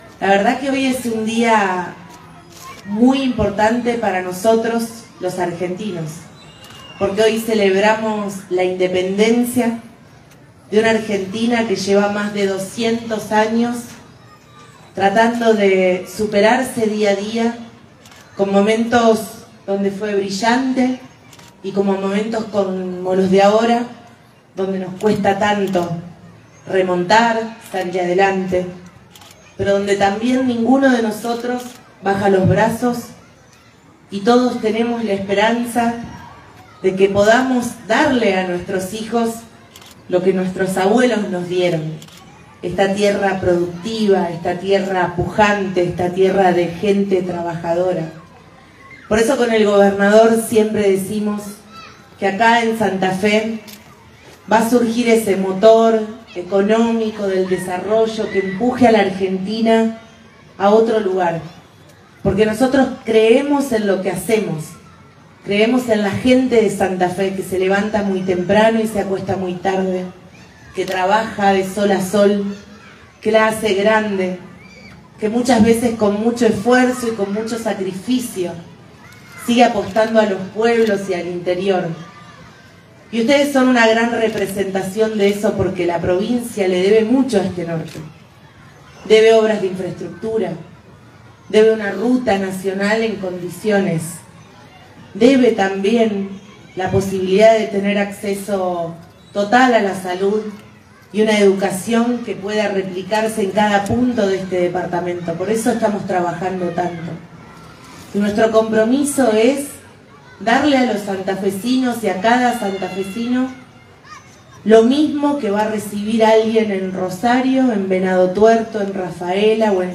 En un acto presidido por la Vicegobernadora de la Provincia de Santa Fe, Lic. Gisela Scaglia y la Intendente Municipal, Ing. Paula Mitre, acompañadas por el Senador, Osvaldo Sosa, Concejales y demás autoridades, la Municipalidad de Vera realizó el acto oficial en conmemoración al 9 de Julio de 1816.
Vicegobernadora de Santa Fe – Gisela Scaglia